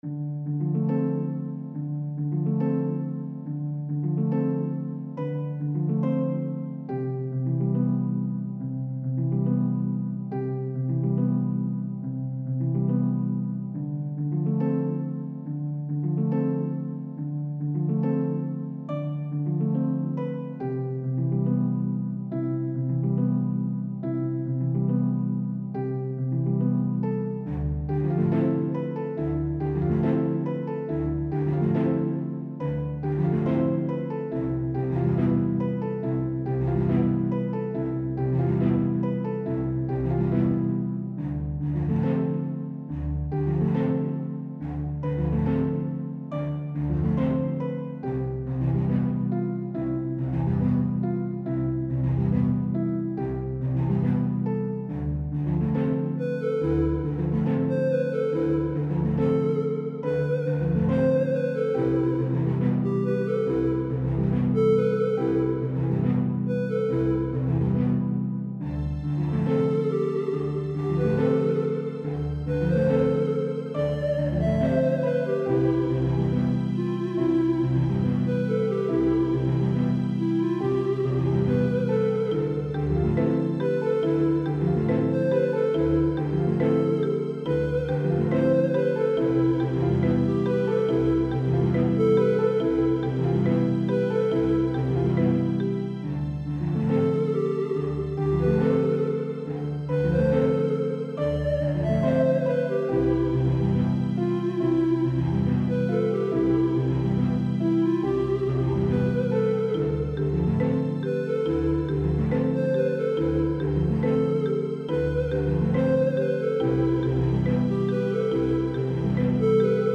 Well I was playing with Undertale soundfonts and made this